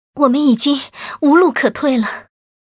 文件 文件历史 文件用途 全域文件用途 Erze_fw_07.ogg （Ogg Vorbis声音文件，长度2.7秒，86 kbps，文件大小：28 KB） 源地址:地下城与勇士游戏语音 文件历史 点击某个日期/时间查看对应时刻的文件。